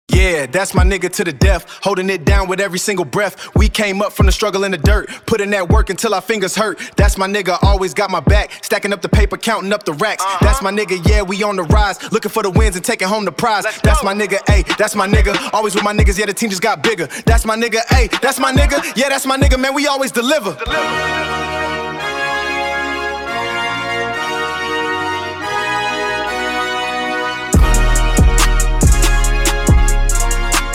Category:Rap